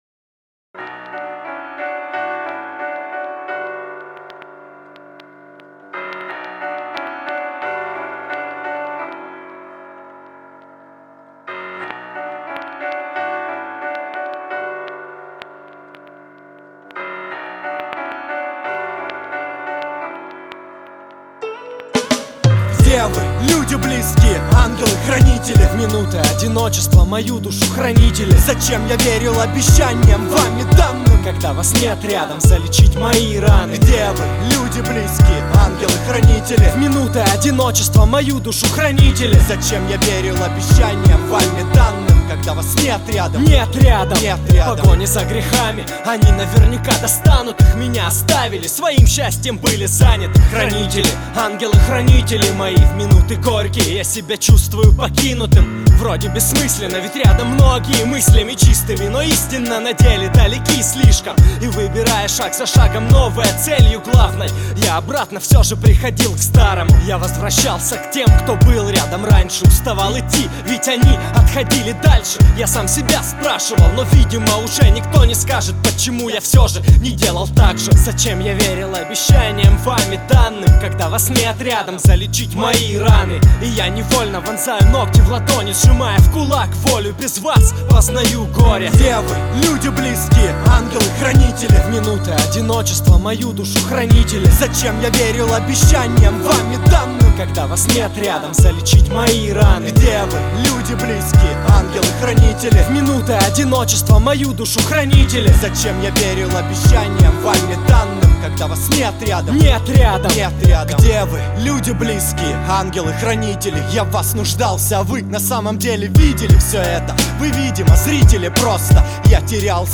Categoria: Rap